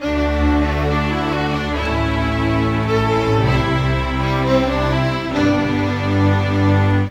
Rock-Pop 01 Strings 01.wav